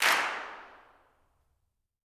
CLAPS 07.wav